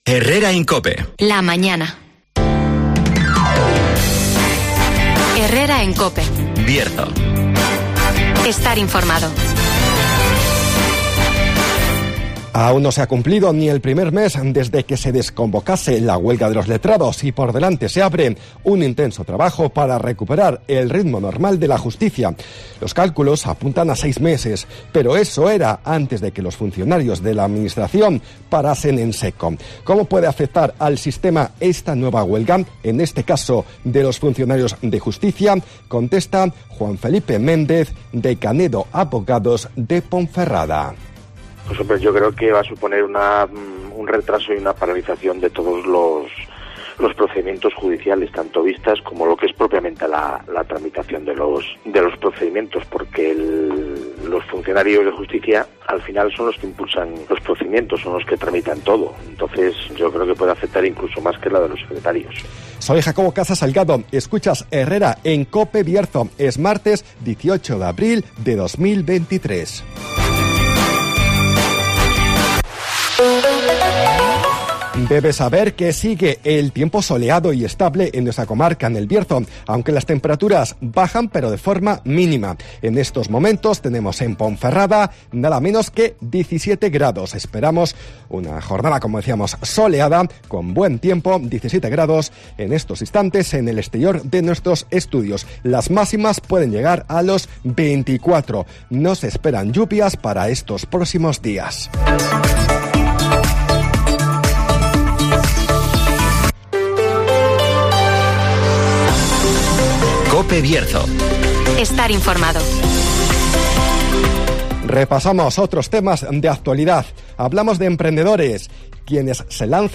-Resumen de las noticias -El tiempo -Agenda -Arranca la iniciativa ‘Rutas Saludables’ de la Asociación Contra el Cáncer de Ponferrada (Entrevista